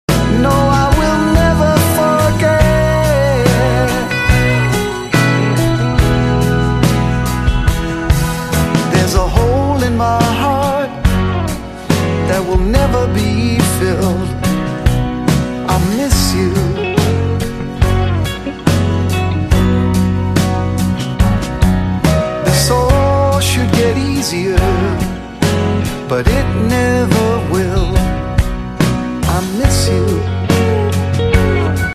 M4R铃声, MP3铃声, 欧美歌曲 45 首发日期：2018-05-14 09:09 星期一